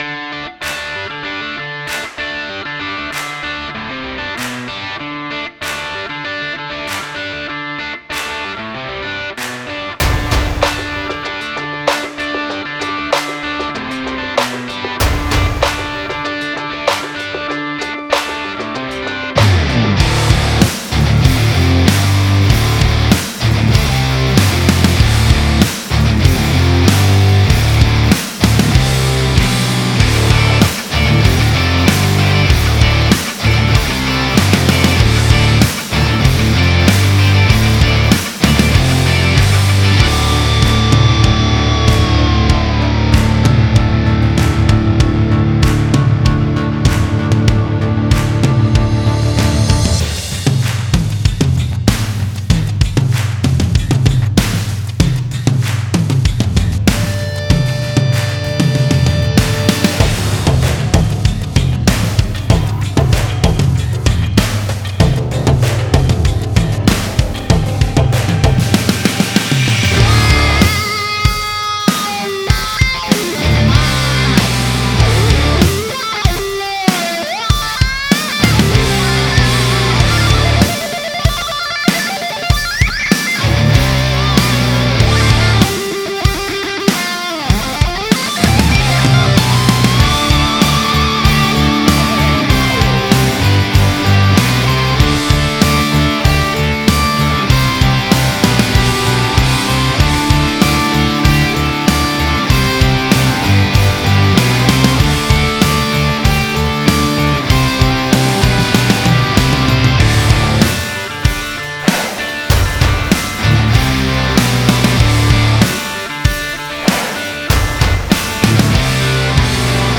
Modern Rock (Glam?)